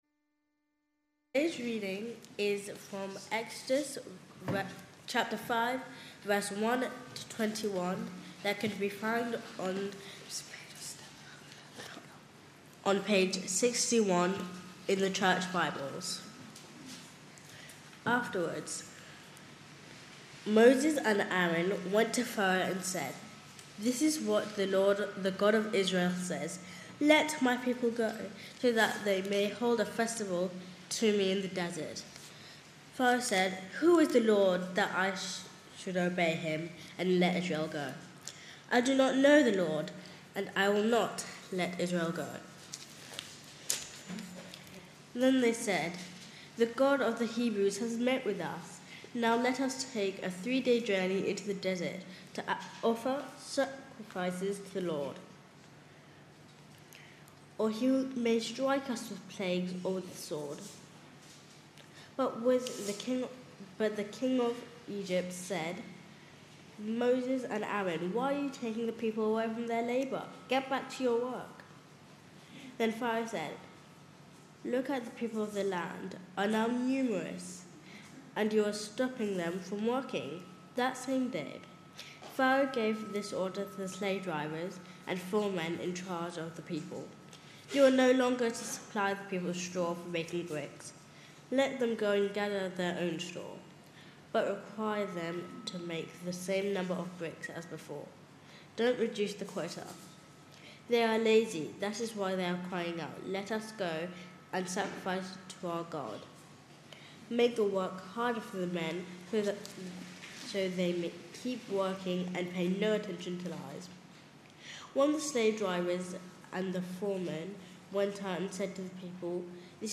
Readings-Sermon-on-8th-February-2026.mp3